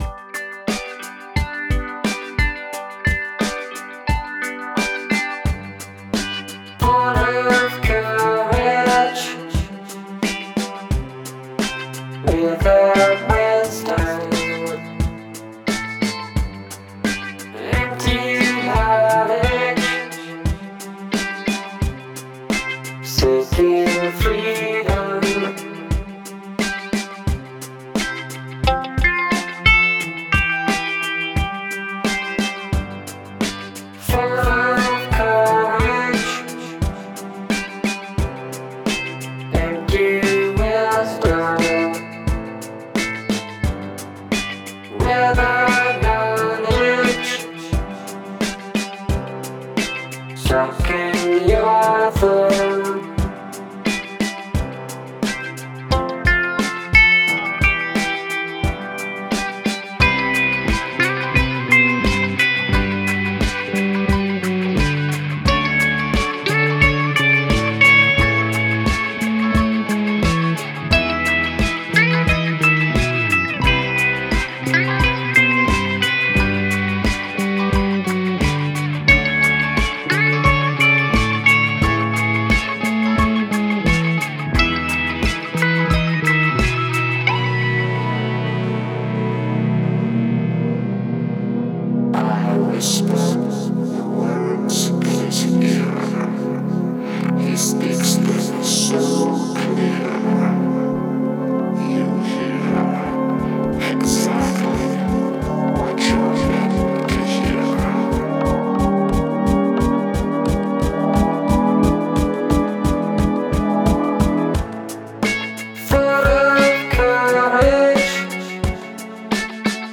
Must include a spoken word bridge